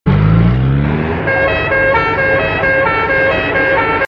BOAT ENGINE AND MELODIC HORN.mp3
A melodic foghorn yelled by a small ship in near waters.
boat_engine_and_melodic_horn_bvw.ogg